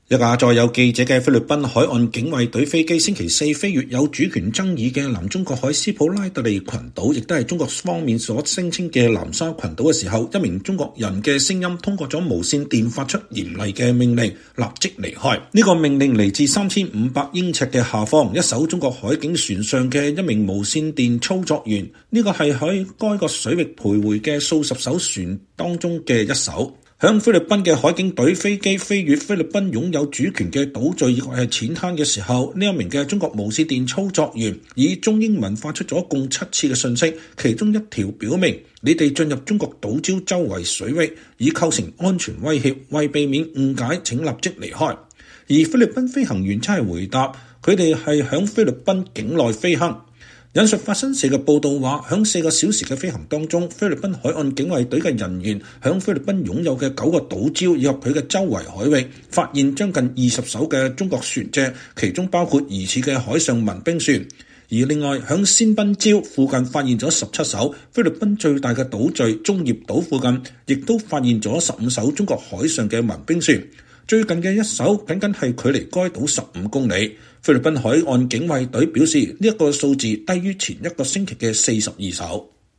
一架載有記者的菲律賓海岸警衛隊(Philippine Coast Guard)飛機星期四飛越有主權爭議的南中國海斯普拉特利群島(Spratly Islands，中國稱“南沙群島”)時，一名中國人的聲音通過無線電發出嚴厲的命令：“立即離開。”